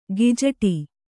♪ gijaṭi